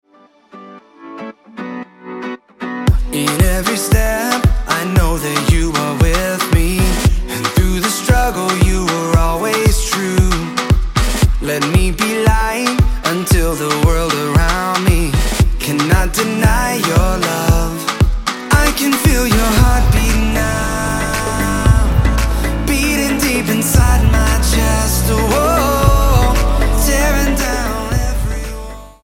STYLE: Pop
EDM-styled